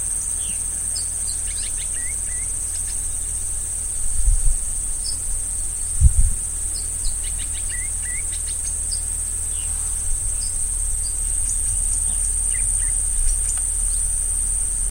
Bluish-grey Saltator (Saltator coerulescens)
Province / Department: Corrientes
Location or protected area: Camino del Camba Trapo
Condition: Wild
Certainty: Recorded vocal